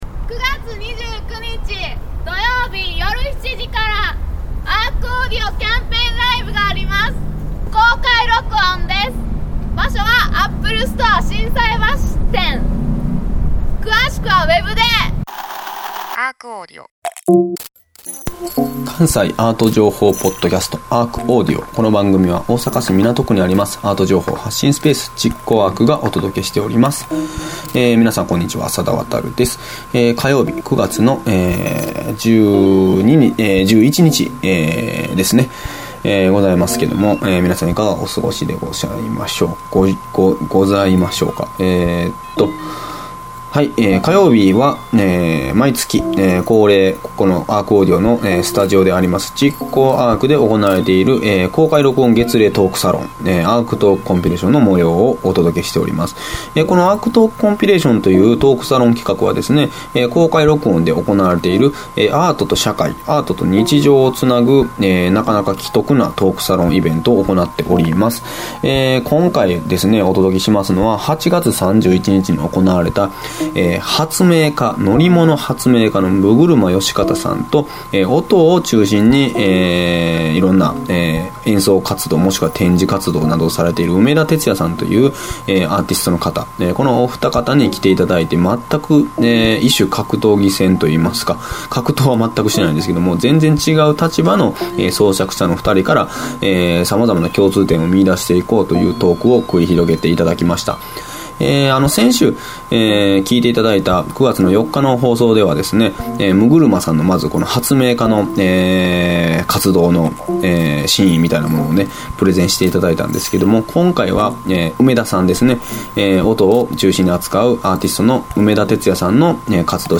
ライブ映像を使用しての活動紹介でしたので音声のみではわかりにくい点があります。